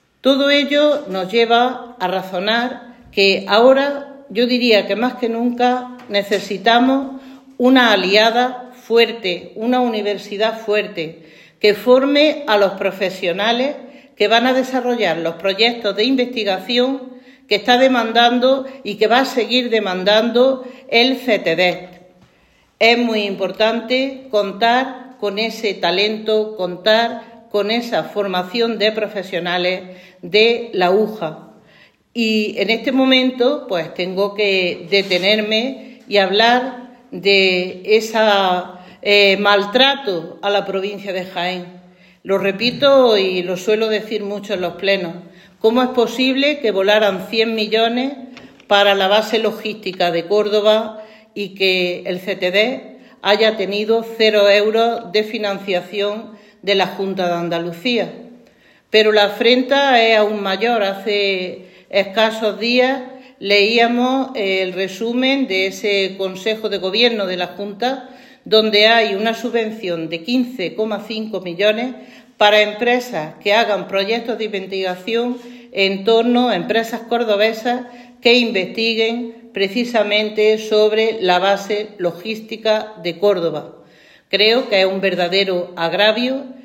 En rueda de prensa
Cortes de sonido